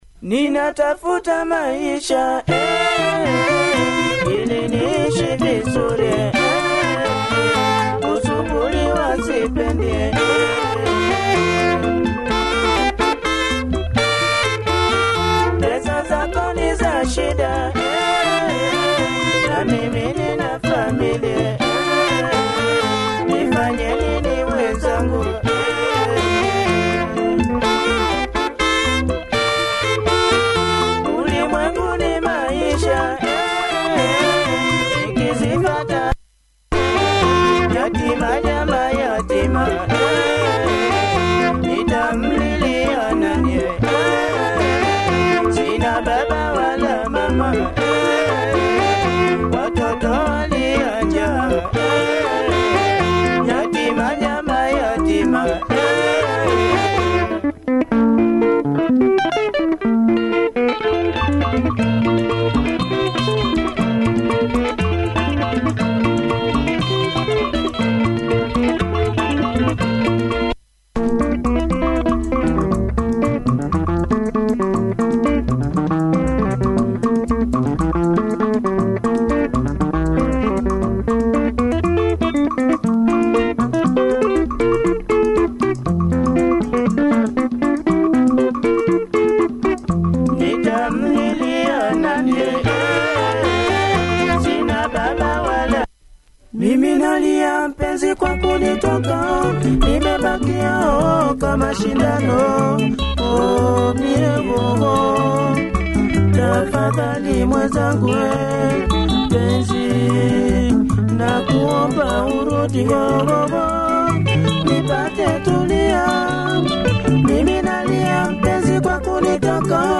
Catchy
jazz number